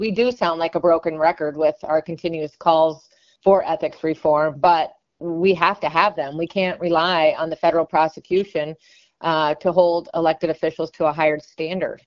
Illinois House Minority Leader Tony McCombie said you can’t put a number on Madigan’s cost to citizens.